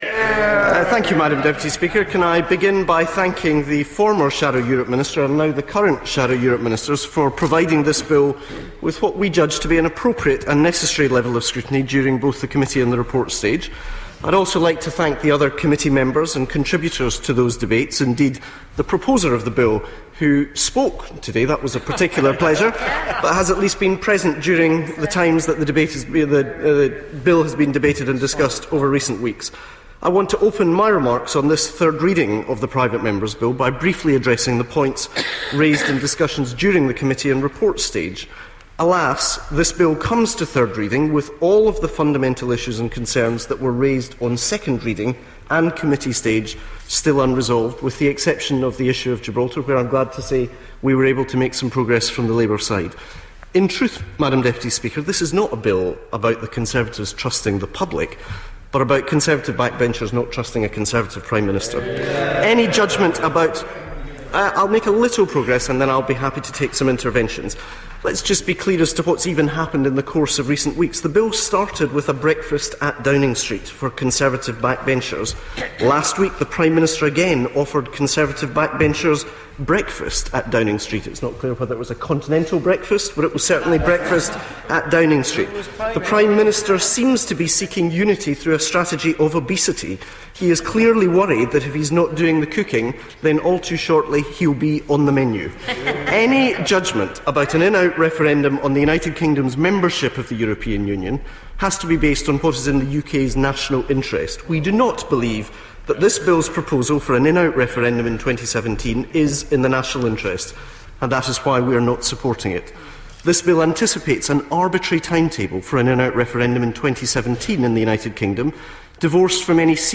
Douglas Alexander on the European Union (Referendum) Bill, House of Commons, 29 November 2013